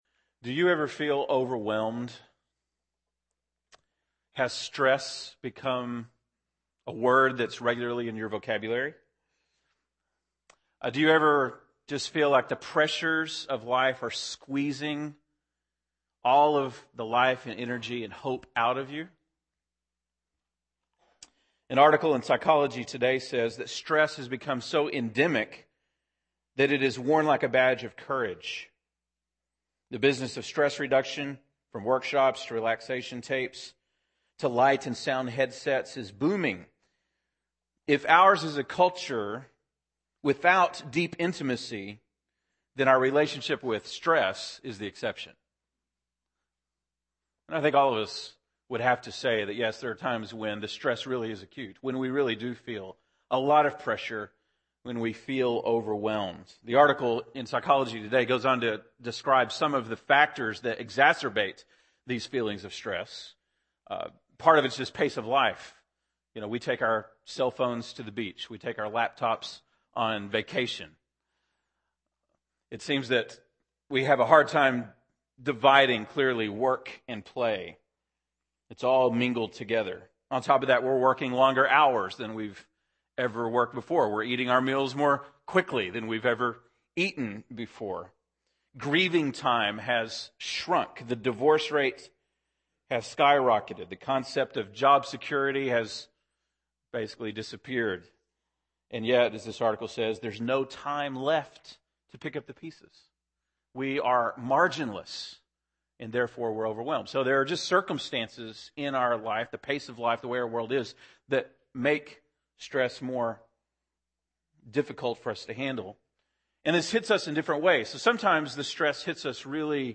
September 19, 2010 (Sunday Morning)